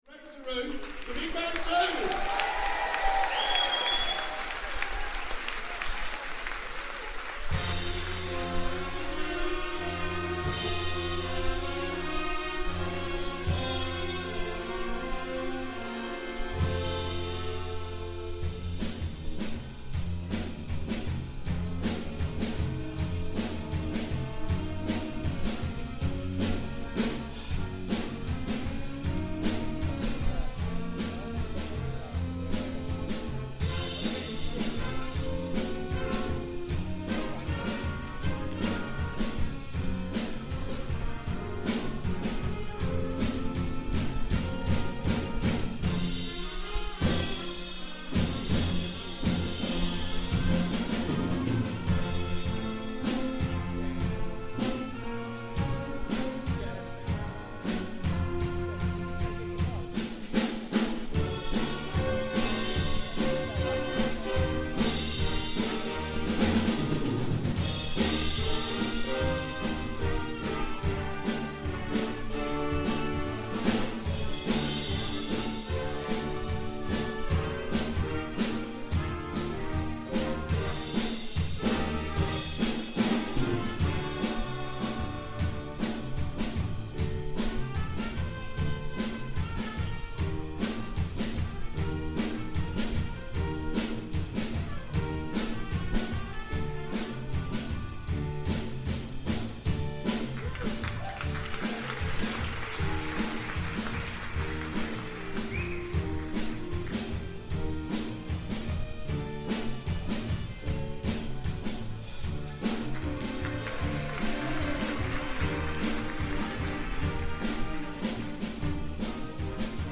Big Band Evening - 1st Half